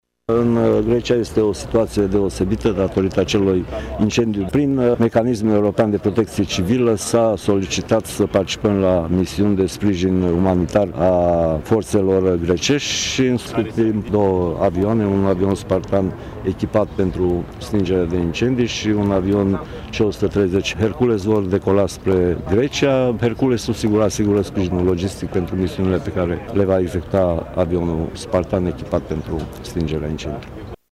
Secretarul de stat Mircea Dușa, a declarat azi, la Tg.Mureș, că țara noastră pune la dispoziție două aeronave , una pentru stingerea incendiilor și alta pentru sprijin logistic.